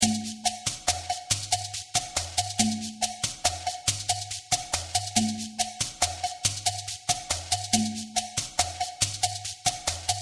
Bembe de Chekere
Bembe_de_chekeres.mp3